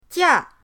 jia4.mp3